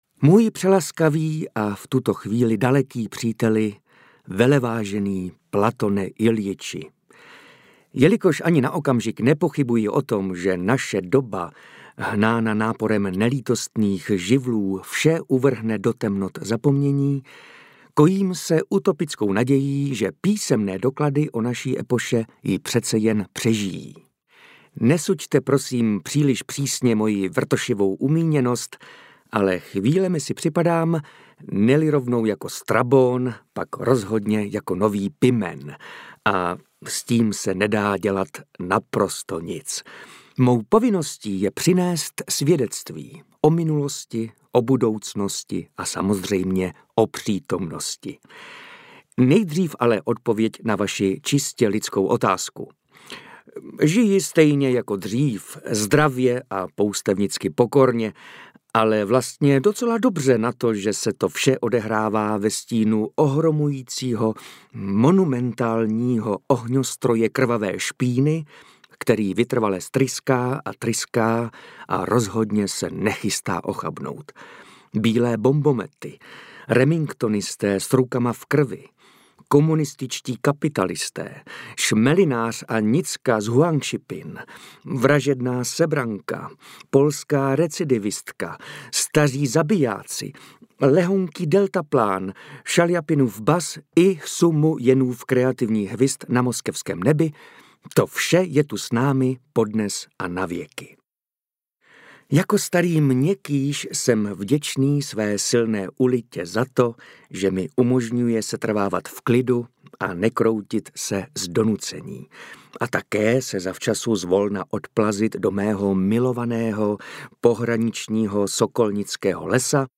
Audiobook
Read: Martin Myšička